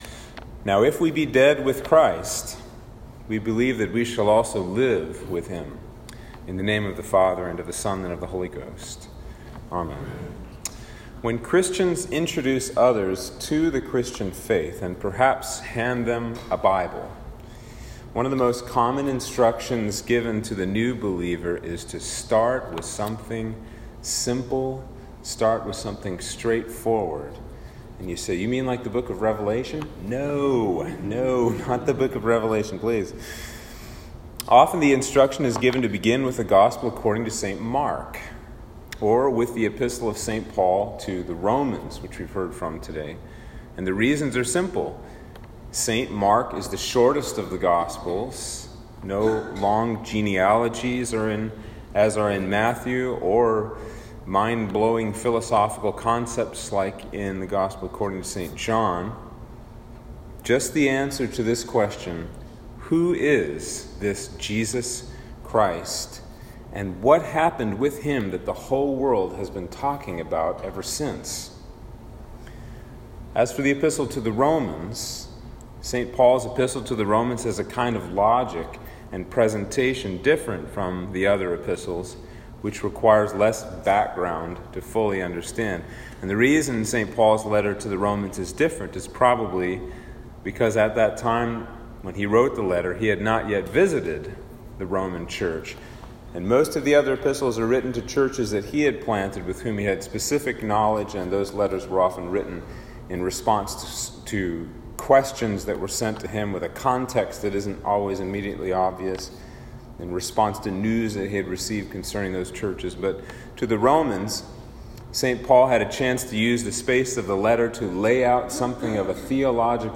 Sermon for Trinity 6
Sermon-for-Trinity-7-2021.m4a